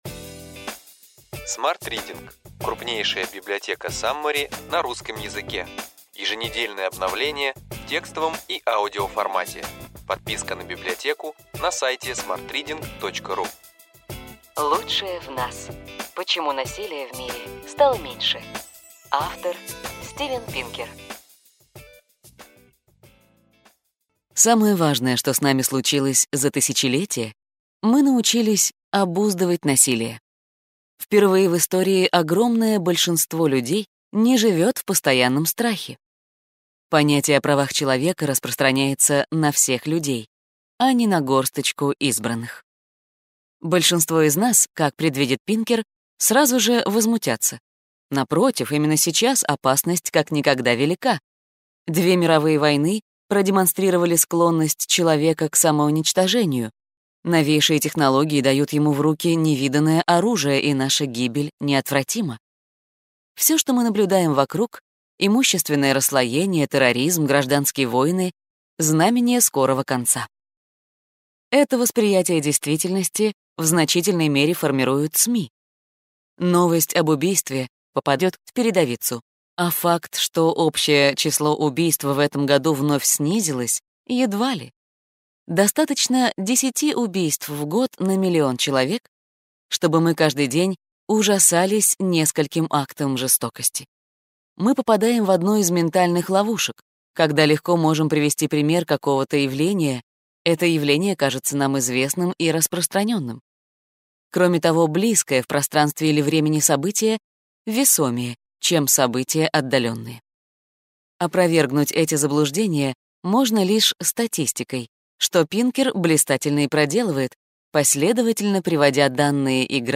Аудиокнига Ключевые идеи книги: Лучшее в нас. Почему насилия в мире стало меньше. Стивен Пинкер | Библиотека аудиокниг